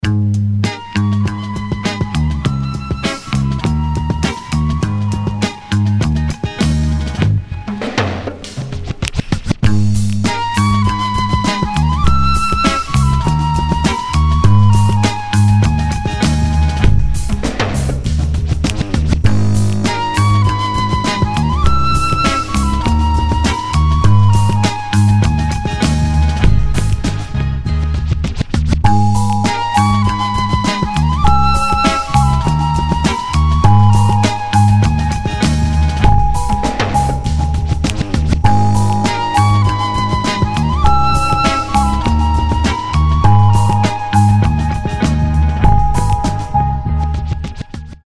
красивые
remix
Хип-хоп
спокойные
инструментальные
из рекламы